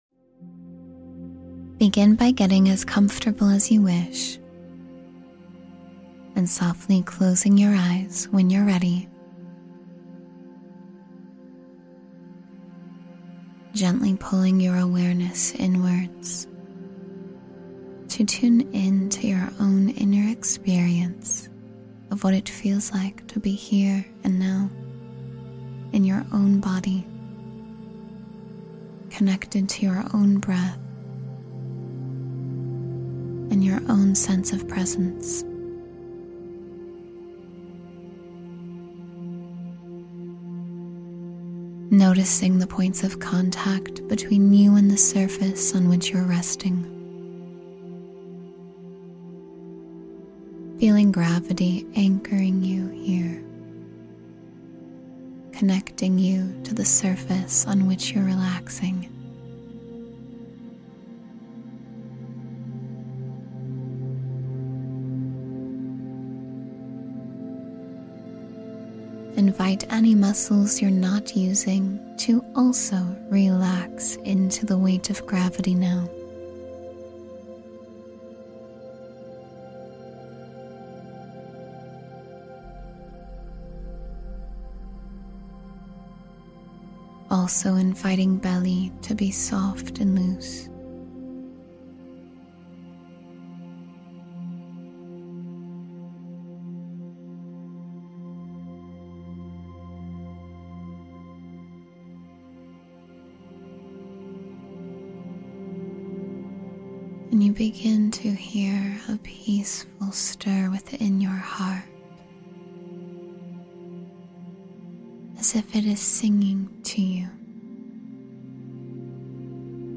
A Bright Future Is Unfolding for You — Guided Meditation for Hope and Inspiration